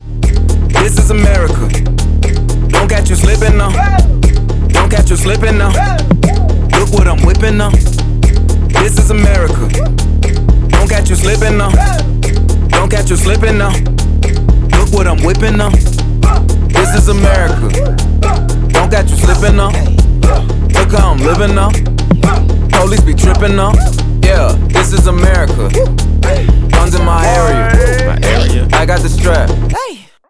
• Качество: 128, Stereo
ритмичные
мощные басы
Trap
качающие
Rap
Bass
устрашающие
Мрачная рэпчина.